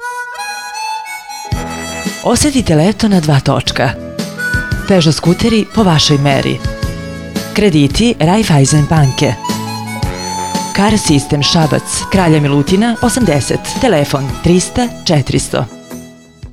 Radio reklame